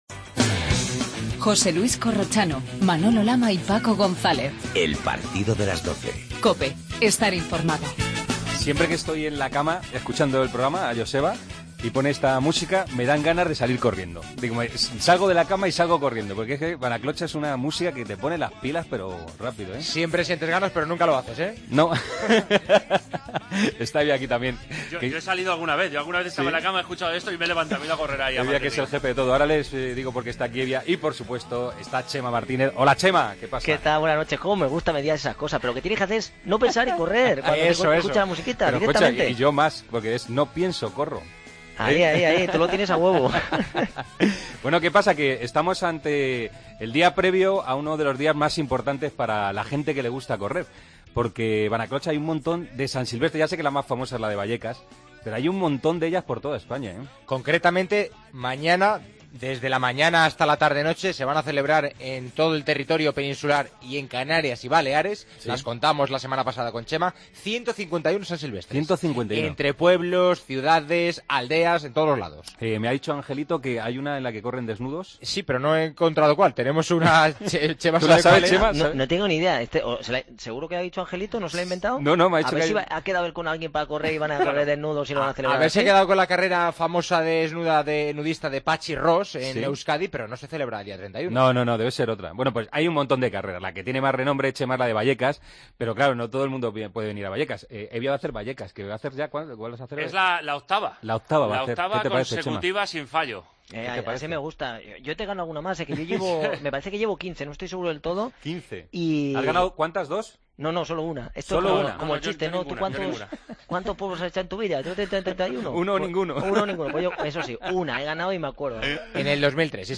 AUDIO: Especial San Silvestre con Chema Martínez. La actriz Marta Larralde nos cuenta su experiencia en el 'running'.